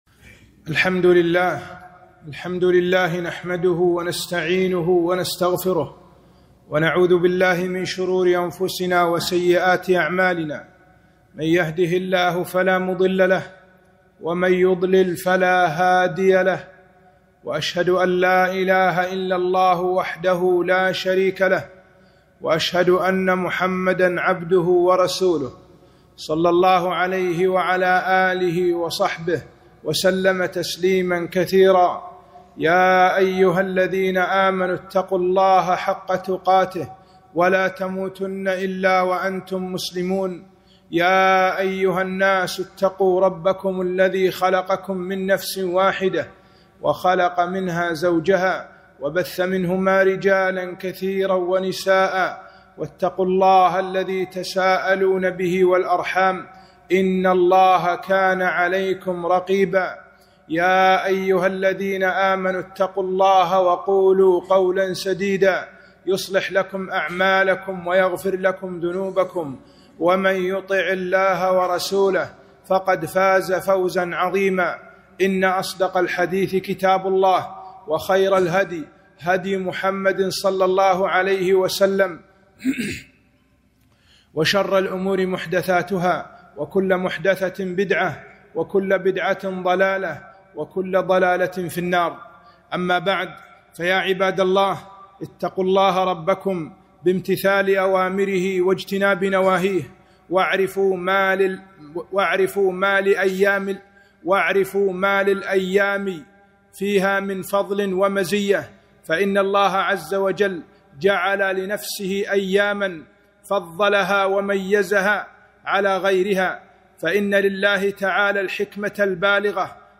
خطبة - فضل العشر الآواخر